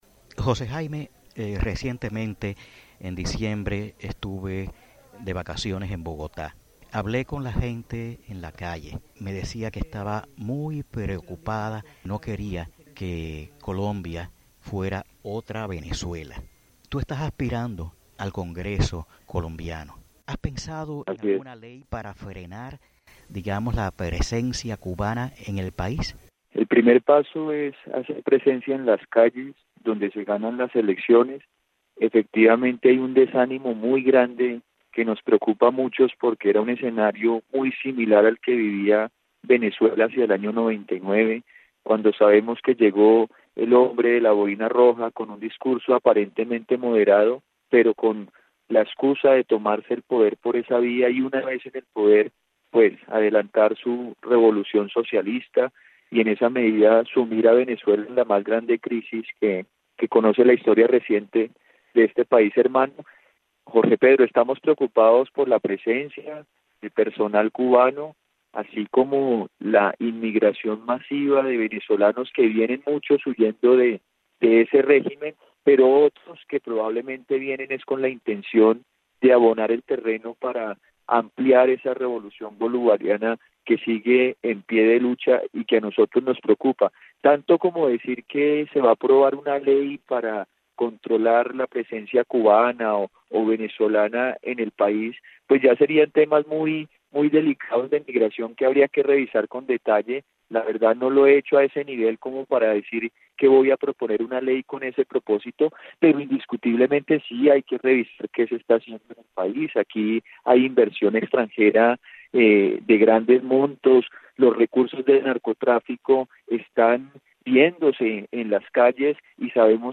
José Jaime Uscátegui, entrevista completa